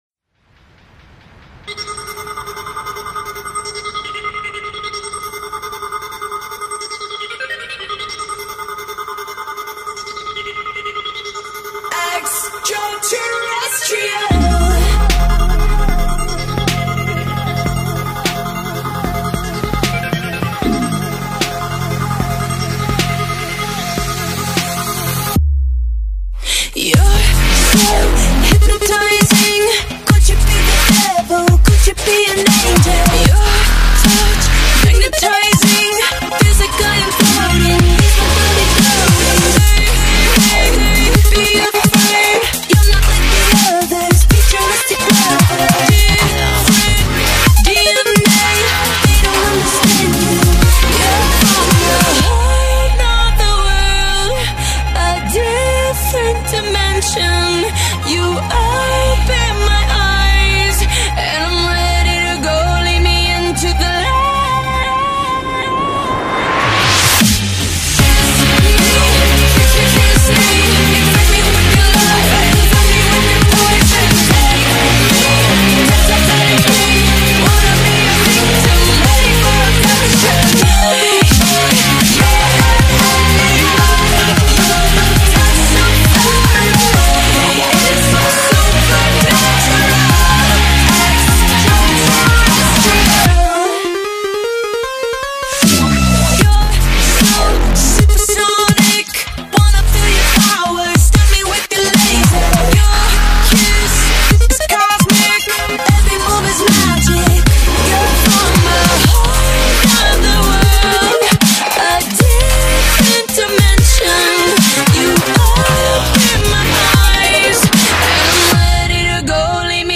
Dubstep Remix